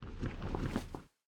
catch_air_6.ogg